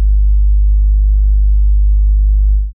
CashMoneyAp Sub (2).wav